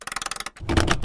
clunk.wav